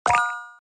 拧完螺丝后播一遍.MP3